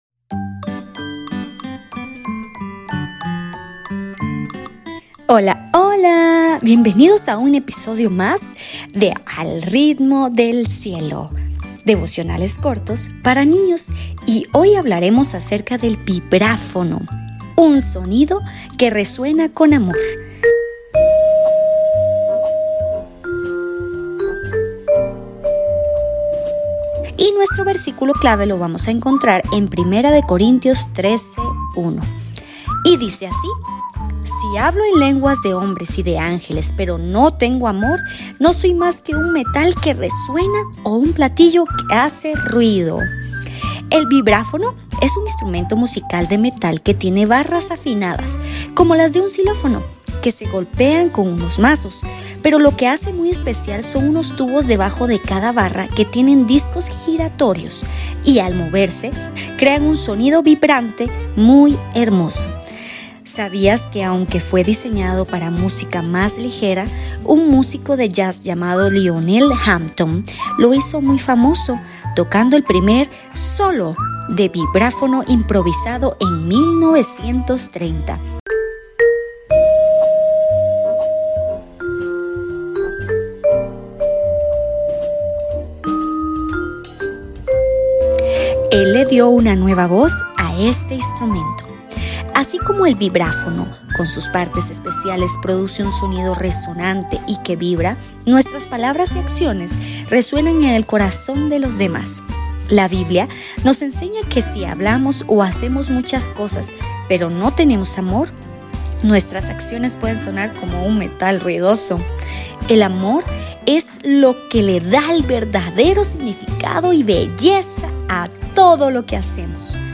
¡Al Ritmo del Cielo! – Devocionales para Niños